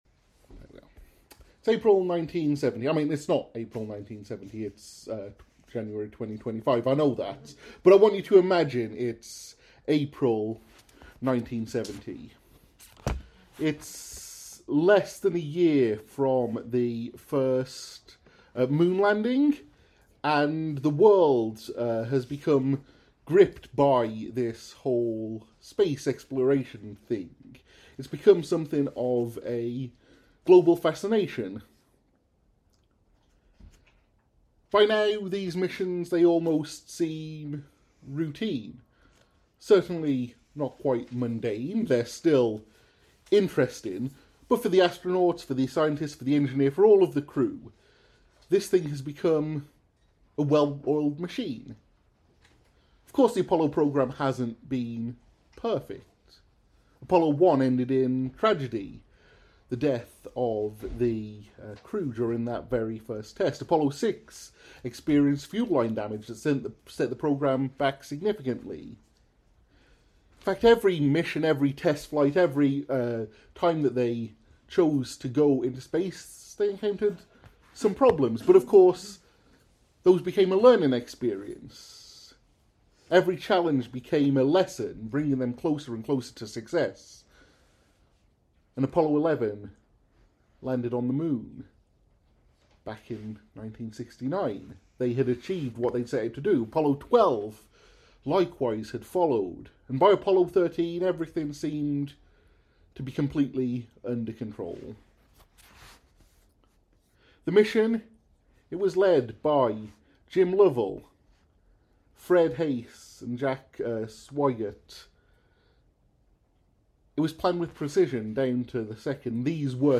In this sermon on John 21:1-14, Jesus’ disciples, are forced to con…